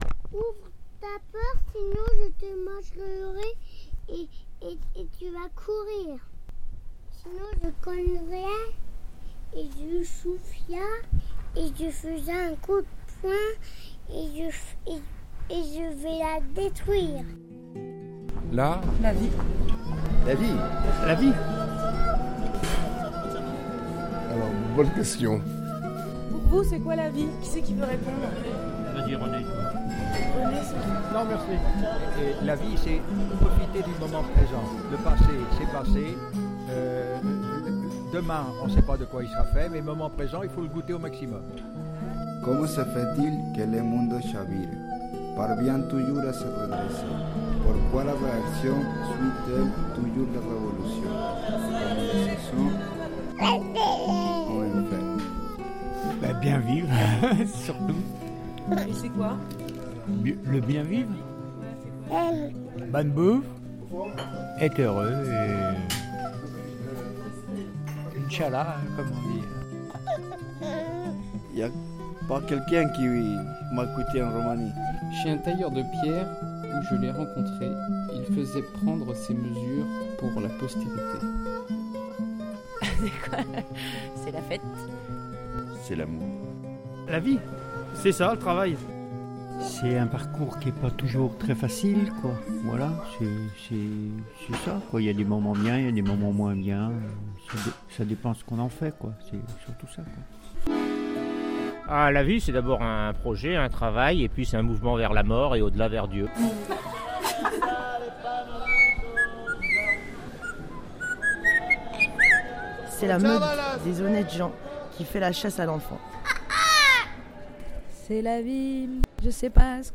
Abracadaboum est une émission (mensuelle) de radio avec des vrais morceaux de vie dedans constituée à partir d’enregistrements spontanés répondant tous à la même question posée inlassablement et obsessionnellement : « pour toi, c’est quoi la vie ? » (avec quelques variantes), de poèmes, de textes, de sons de la vie, de morceaux de musique…